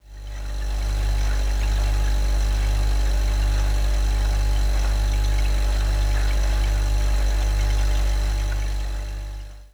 Kühlschrank
Wer kennt es nicht, das Geräusch, das man hört wenn man um 5:30 Morgens in der Küche sitzt und halbverschlafen sein Frühstück zu sich nimmt. Richtig -Der Kühlschrank summt in einem fort...
kuehlschrank